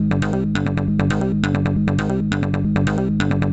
• techno synth bass sequence 136 1.wav
techno_synth_bass_sequence_136_1_6Lq.wav